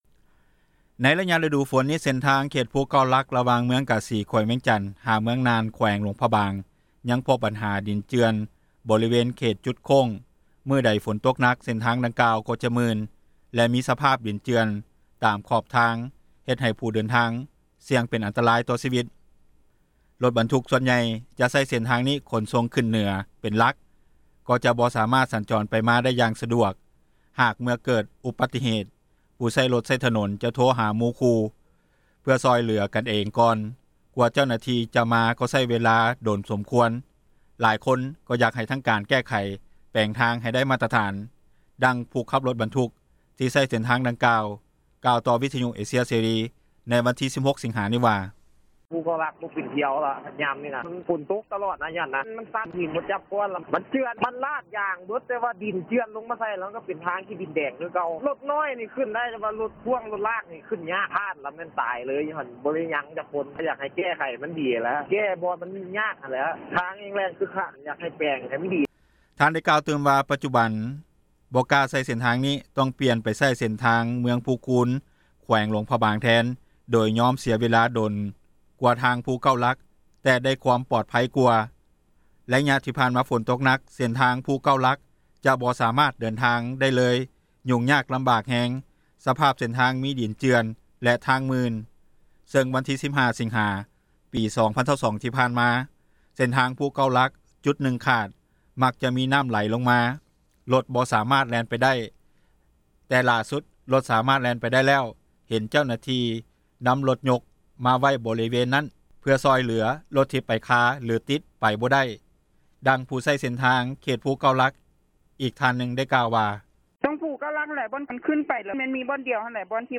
ດັ່ງຜູ້ຂັບຣົຖບັນທຸກ ທີ່ໃຊ້ເສັ້ນທາງດັ່ງກ່າວກ່າວ ຕໍ່ວິທຍຸເອເຊັຽເສຣີ ໃນວັນທີ 16 ສິງຫານີ້ວ່າ:
ດັ່ງຜູ້ໃຊ້ເສັ້ນທາງ ເຂດພູເກົ້າຫຼັກ ອີກທ່ານນຶ່ງກໍໄດ້ກ່າວວ່າ: